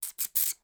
• Hamster Calls
To add to the player’s interest in controlling the hamster, I recorded a series of hamster calls using a human voice and set them to play randomly when the hamster hit the wall in each room.
Hamster_Hiss_2-1.wav